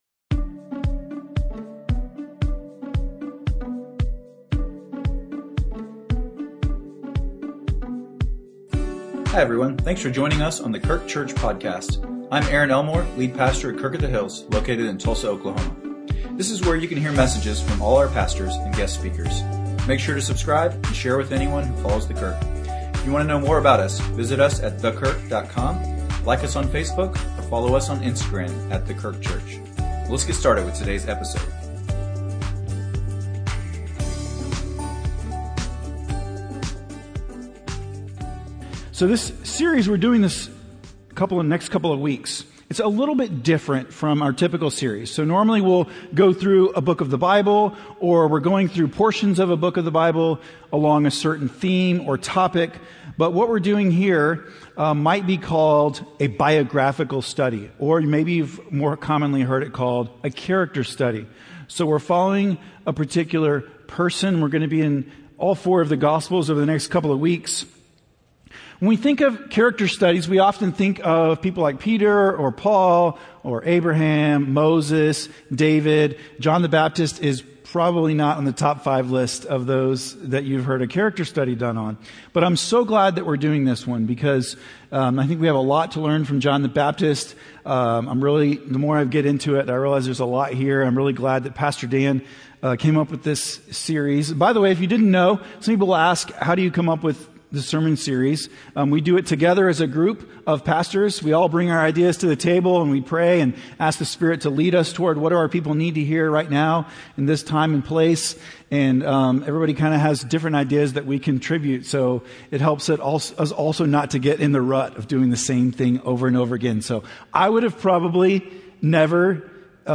A message from the series "Standing Strong."